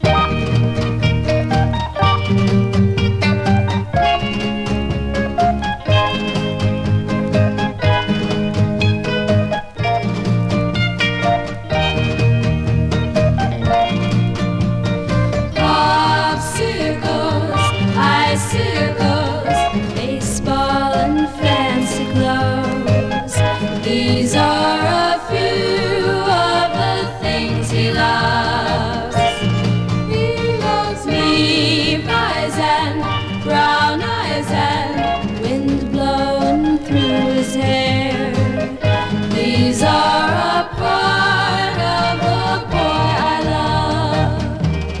Pop, Girl　USA　12inchレコード　33rpm　Mono